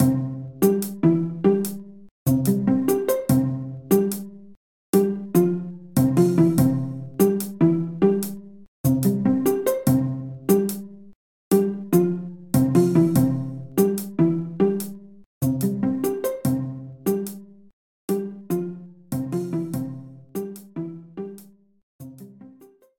Level preview music